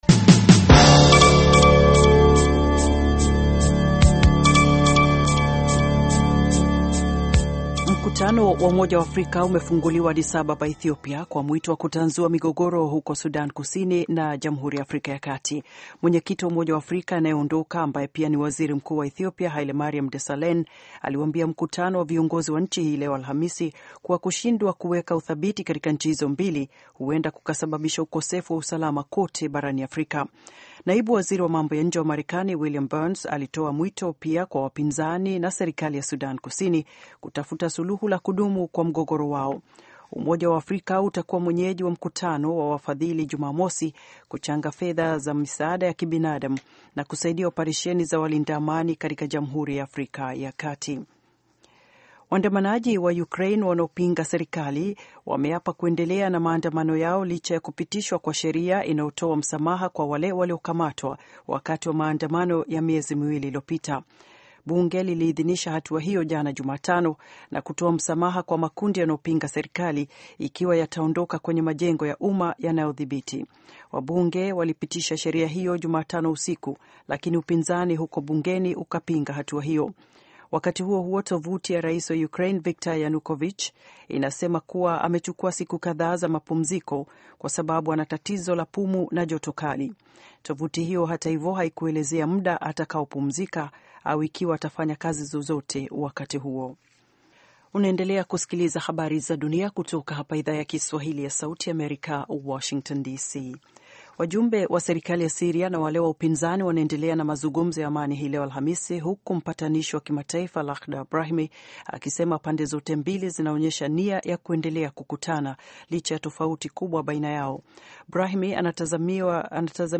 Taarifa ya Habari VOA Swahili - 5:18